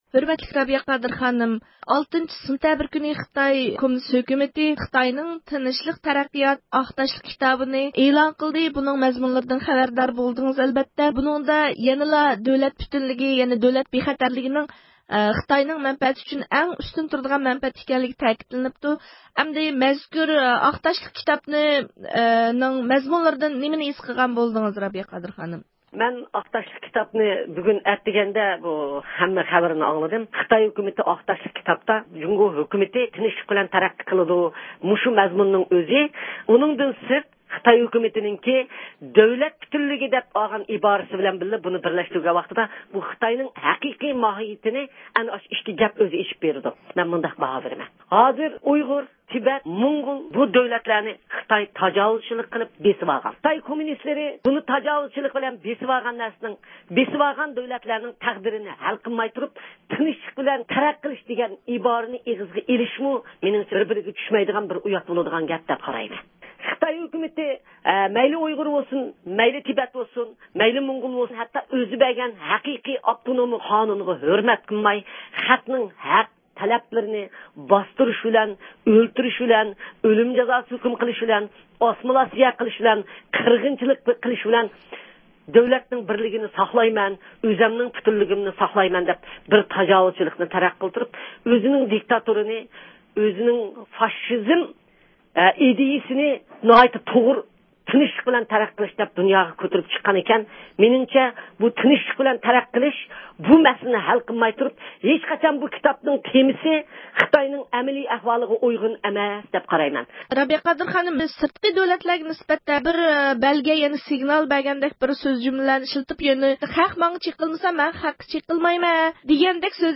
يۇقىرىدىكى ئاۋاز ئۇلىنىشىدىن رابىيە قادىر خانىم بىلەن مۇخبىرىمىزنىڭ بۇ ھەقتە ئۆتكۈزگەن سۆھبىتىگە دىققەت قىلغايسىلەر.